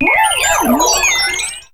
Grito de Shiinotic.ogg
Grito_de_Shiinotic.ogg